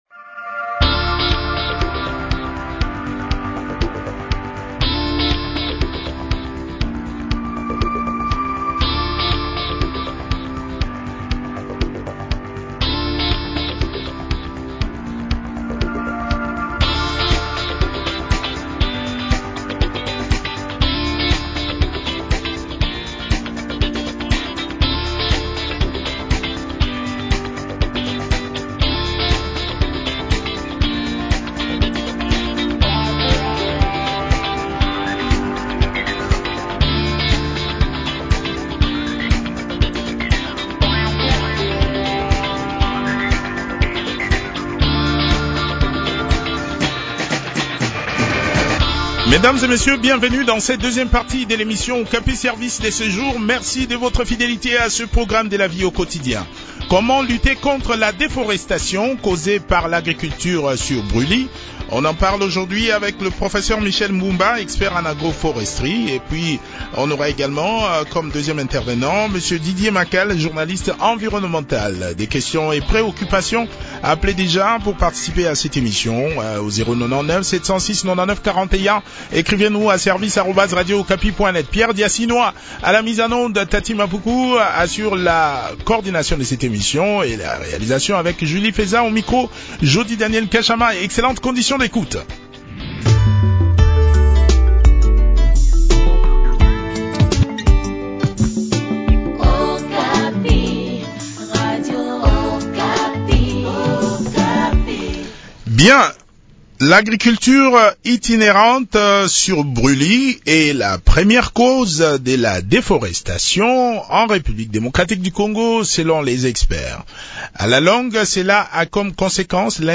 s’entretient sur ce sujet avec le professeur